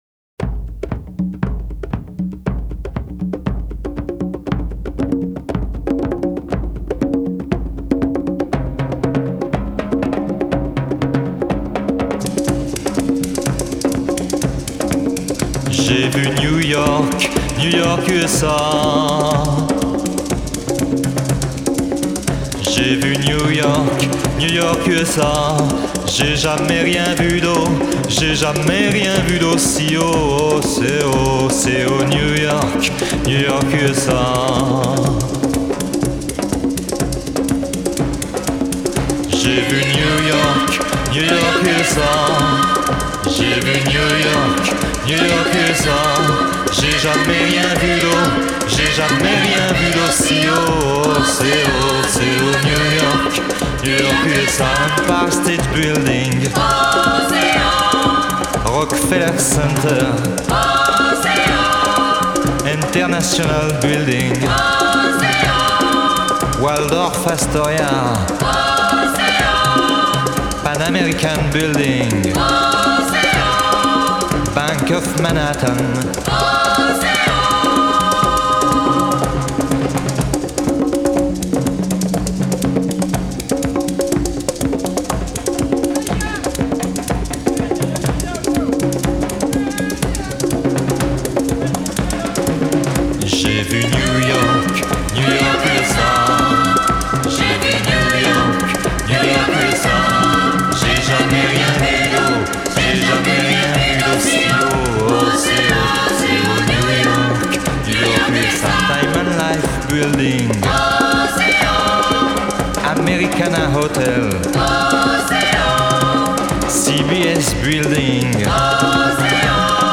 rythmes africains